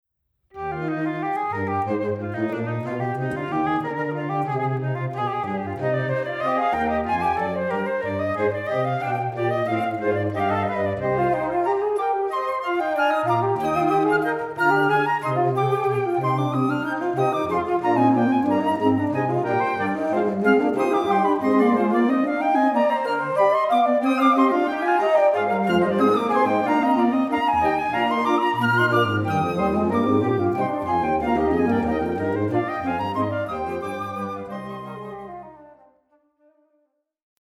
bass, contrabass and sub-contrabass flutes